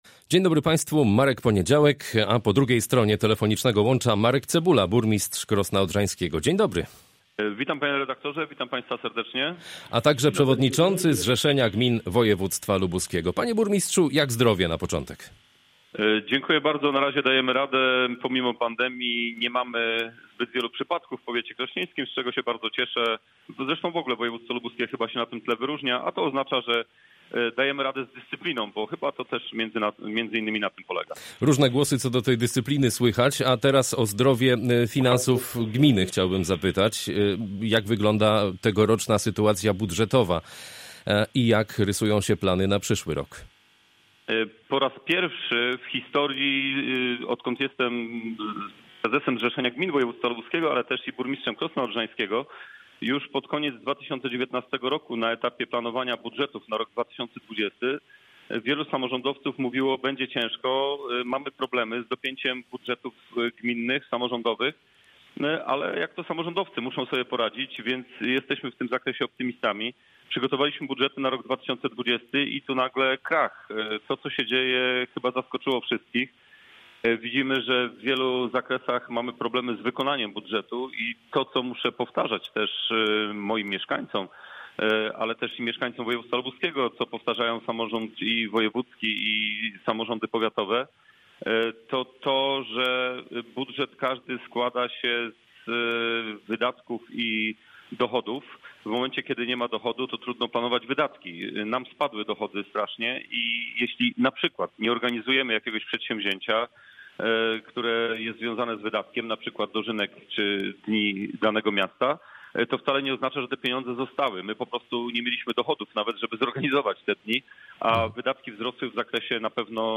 Z burmistrzem Krosna Odrzańskiego, przewodniczącym Zrzeszenia Gmin Województwa Lubuskiego rozmawia